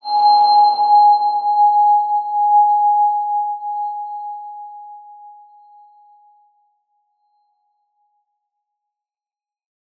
X_BasicBells-G#3-ff.wav